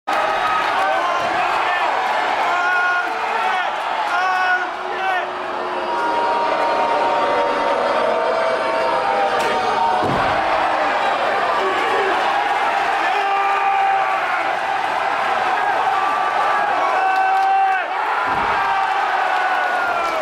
THE CROWD WAS GOING CRAZY Sound Effects Free Download